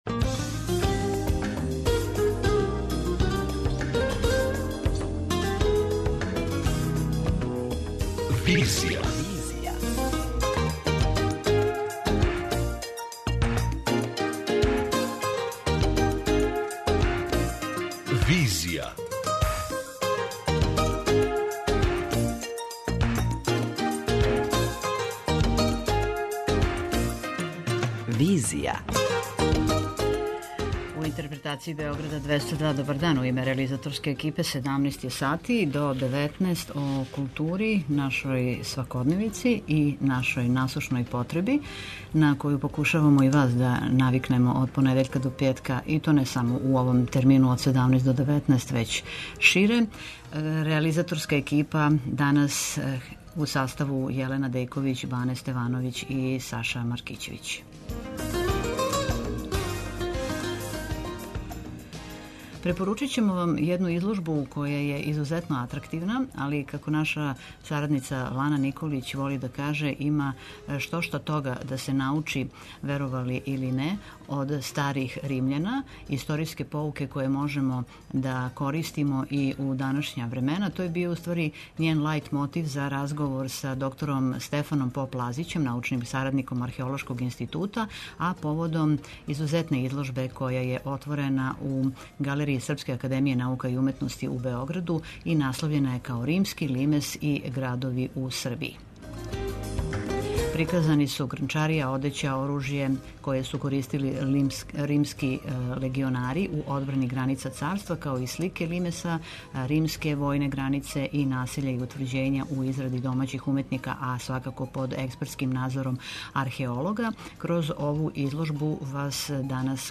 преузми : 53.97 MB Визија Autor: Београд 202 Социо-културолошки магазин, који прати савремене друштвене феномене.